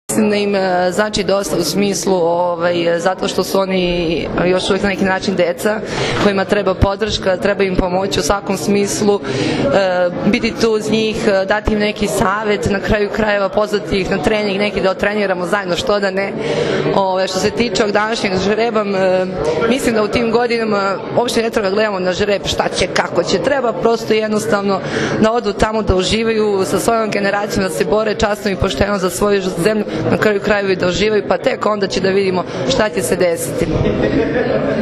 U Palati Srbija danas je obavljen žreb za Svetsko školsko prvenstvo u odbojci, koje će se odigrati od 25. juna – 3. jula u Beogradu.
IZJAVA VESNE ČITAKOVIĆ ĐURIŠIĆ